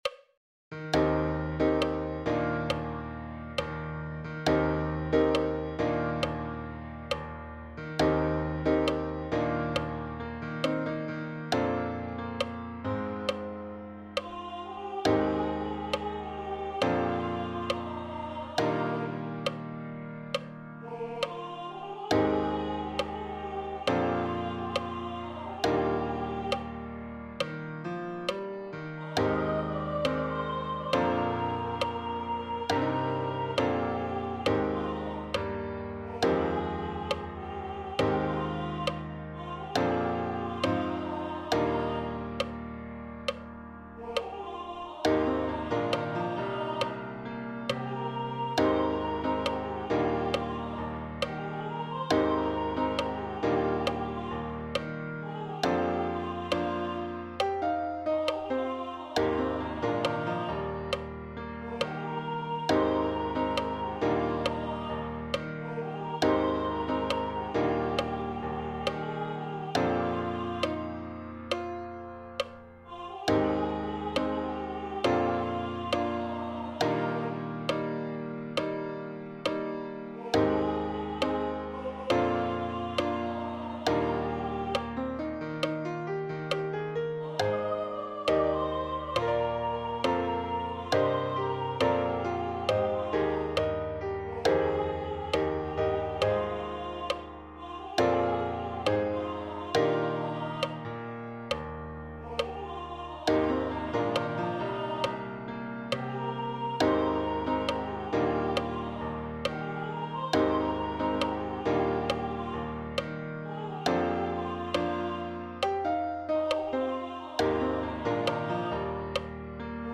For voice and piano.